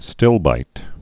(stĭlbīt)